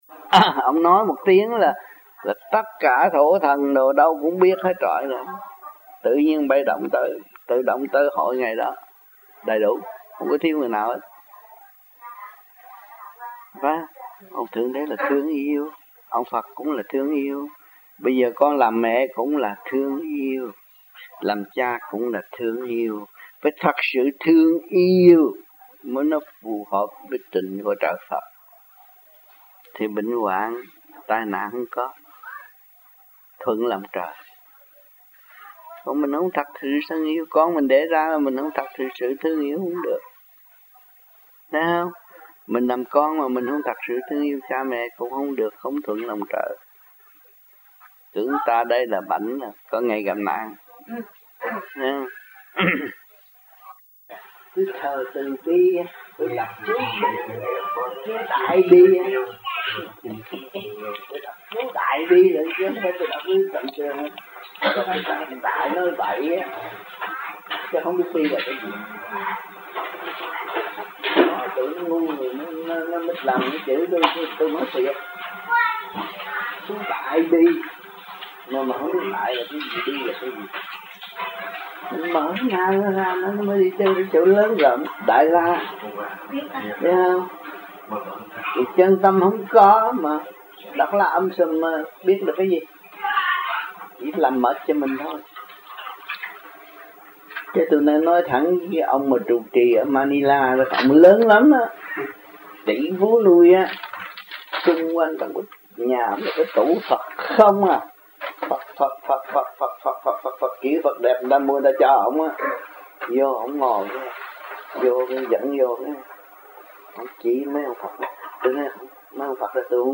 1992 Khóa Học Thiền Đường Thanh Hòa